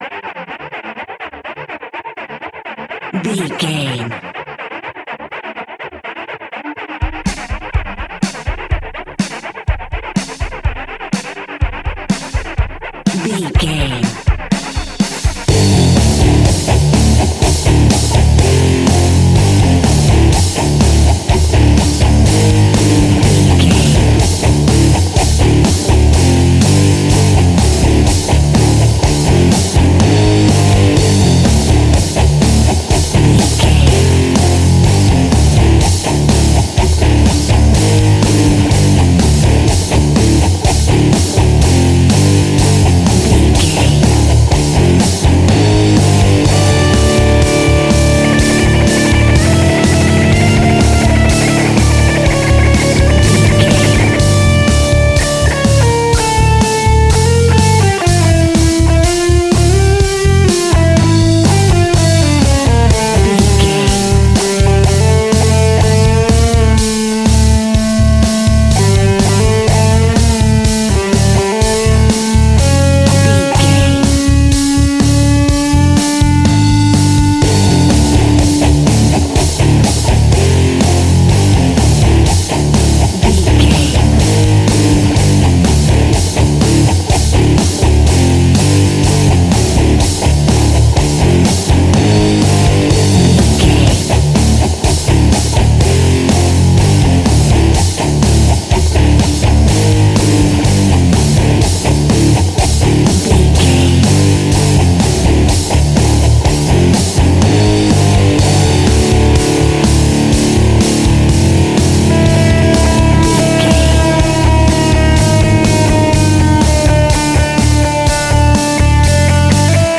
royalty free music
Ionian/Major
energetic
driving
heavy
aggressive
electric guitar
bass guitar
drums
electric organ
hard rock
heavy metal
heavy drums
distorted guitars
hammond organ